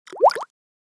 03_Bubbles.ogg